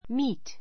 míːt ミ ー ト